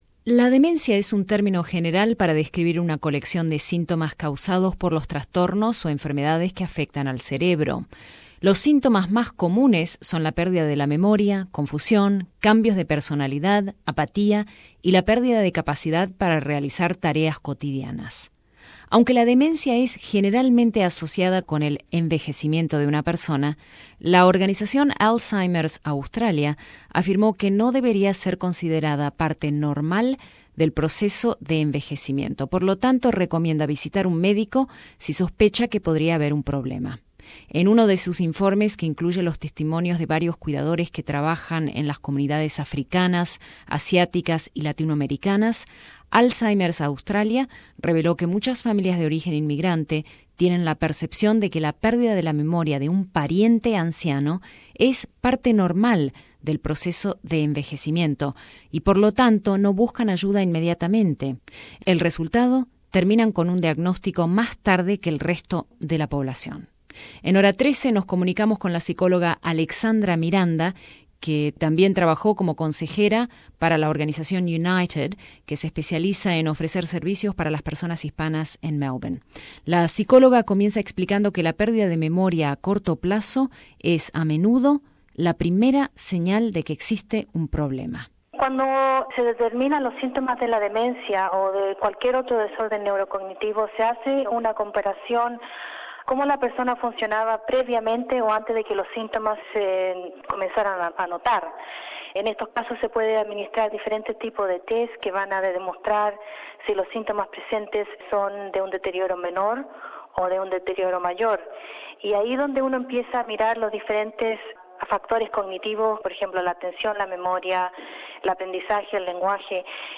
Experta en salud mental explica las causas, síntomas y ayuda disponible en Australia para lidiar con el deterioro mental.